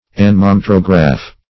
Search Result for " anemometrograph" : The Collaborative International Dictionary of English v.0.48: Anemometrograph \An`e*mo*met"ro*graph\, n. [Anemometer + -graph.]